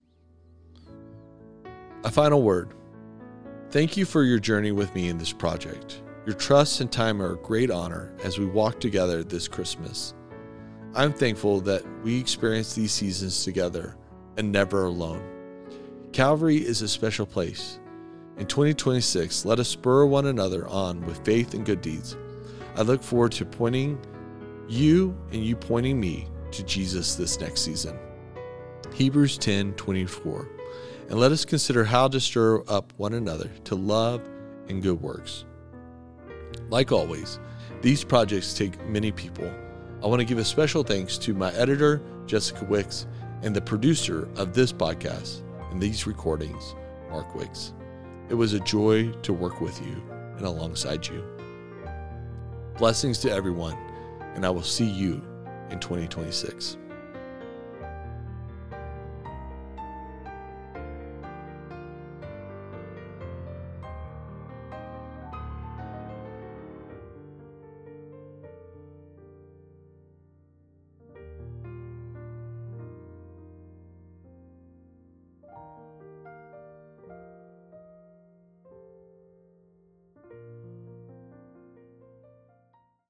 Advent Readings & Prayer